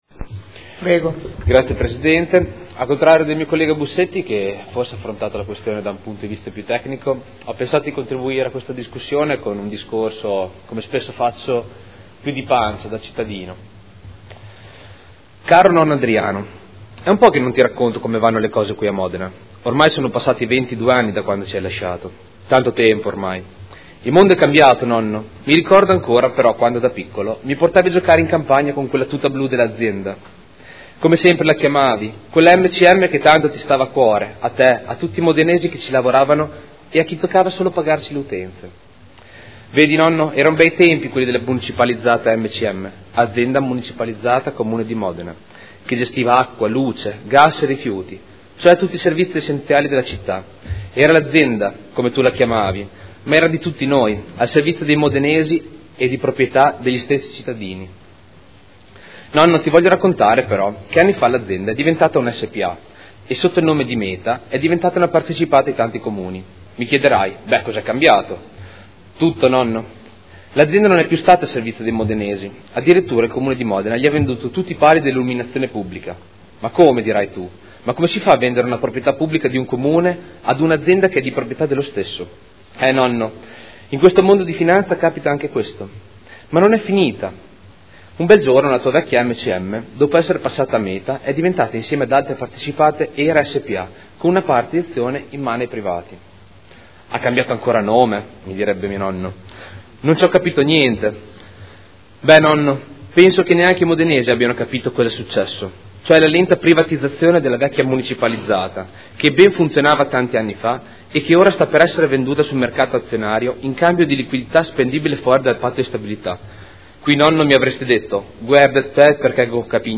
Luca Fantoni — Sito Audio Consiglio Comunale